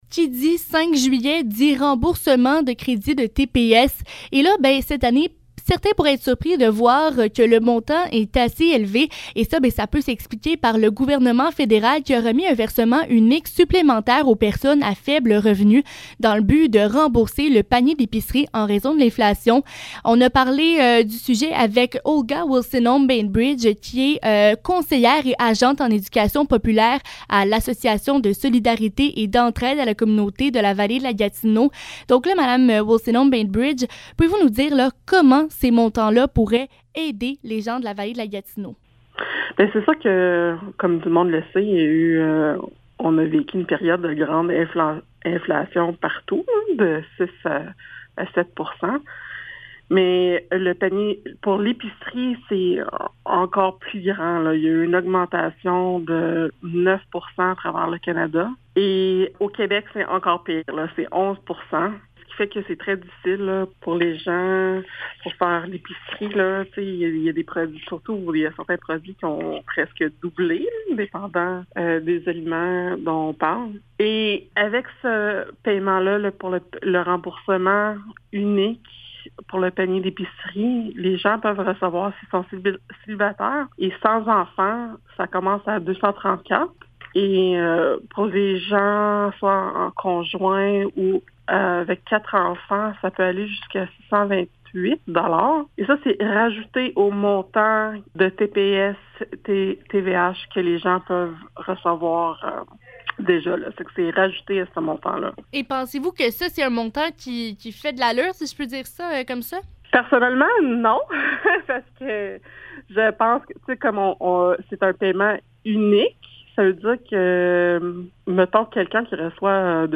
Entrevue sur la somme versée par le fédéral pour l'aide à l'épicerie. Une mesure jugée insuffisante selon l'ASEC.